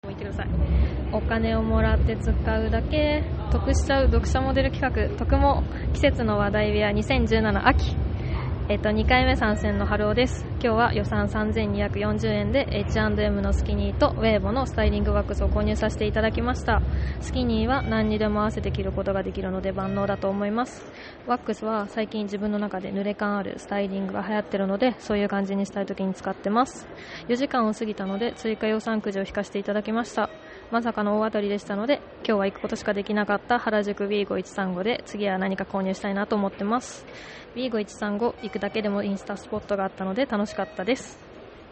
本日の音録りはファミレスで、飲食代はお買い物予算とは別に出るのでご飯代も１回分うきますよ!